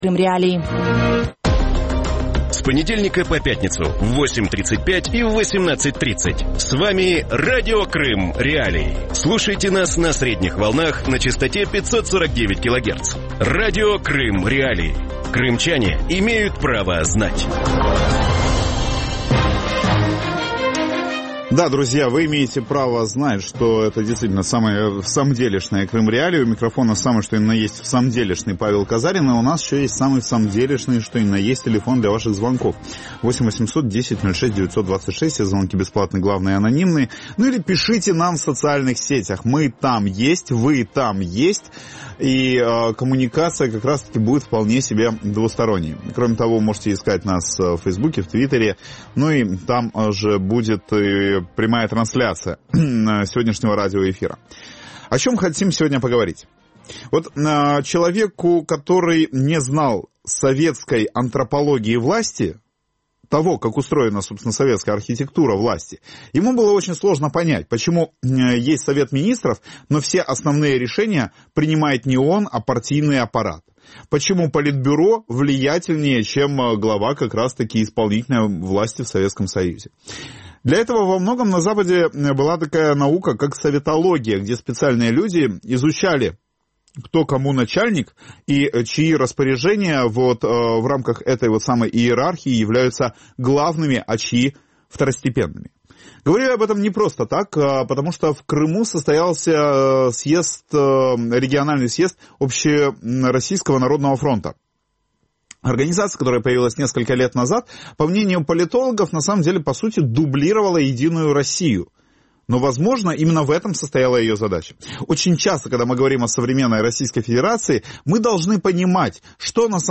В вечернем эфире Радио Крым.Реалии обсуждают участие российского президента Владимира Путина в форуме Общероссийского народного фронта (ОНФ) в аннексированном Крыму. Чем занимается Общероссийский народный фронт на полуострове, как прошел форум и какие обещания дал Владимир Путин крымчанам?